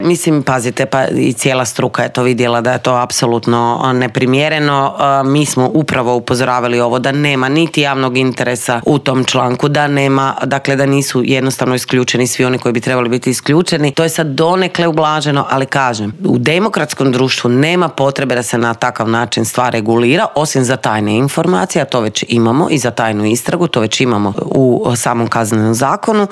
ZAGREB - U Intervjuu tjedna Media servisa gostovala je saborska zastupnica i premijerska kandidatkinja stranke Možemo Sandra Benčić, koja je prokomentirala nove izmjene koje je Vlada najavila uvrstiti u konačni prijedlog tzv. Lex AP-a, osvrnula se na reakciju vladajućih na jučerašnji prosvjed HND-a zbog kaznenog djela o curenju informacija, ali i na kandidata za glavnog državnog odvjetnika Ivana Turudića.